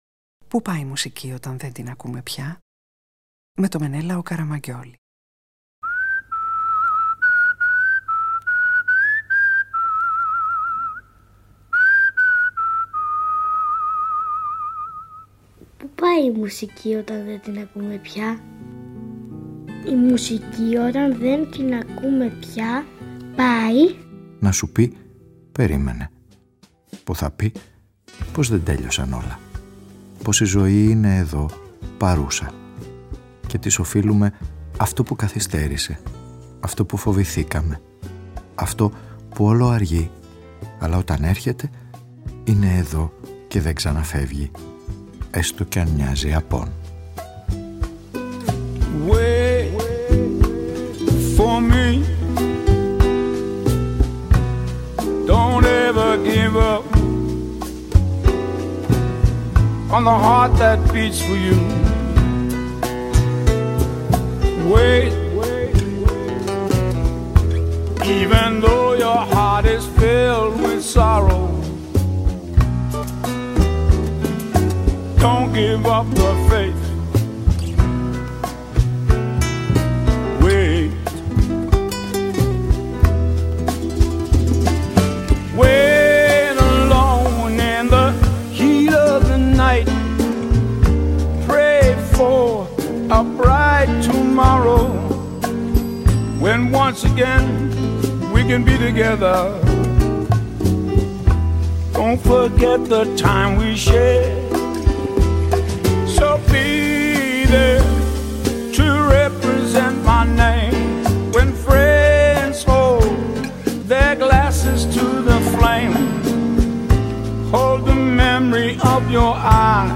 H σημερινή ραδιοφωνική ταινία στήνει ενδελεχή έρευνα να συνδράμει τους απογοητευμένους και να βρει τι συνέβη, τι χάθηκε και τι απομένει ή τι μπορεί να αξιοποιηθεί· με τη βοήθεια των Πεσόα, Ριβαρόλ, Παπαδιαμάντη, Γκανά, Μπουκόφσκι, Αττίκ, Σεφέρη, Γκόλ, Ταρκόφσκι, Σάιφερτ, Παπαδάκη, Κτιστόπουλου.